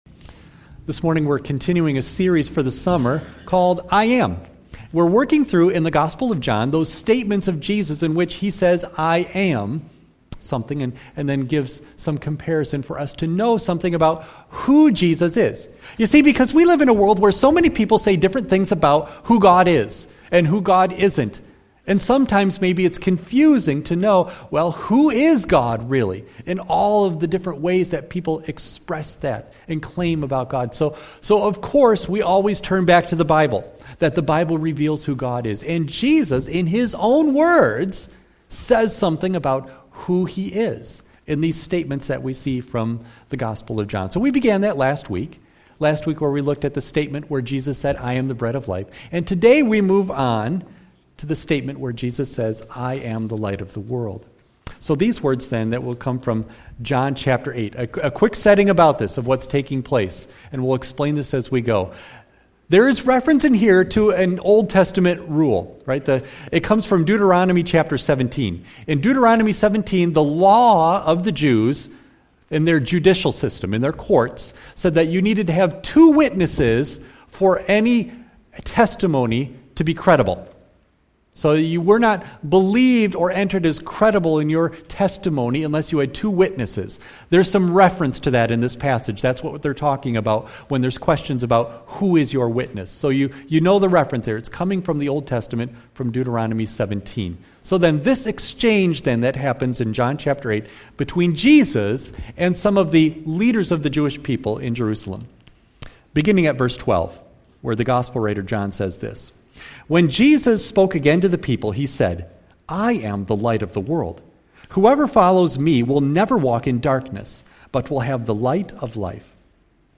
John 8:12-20 Service Type: Sunday AM Bible Text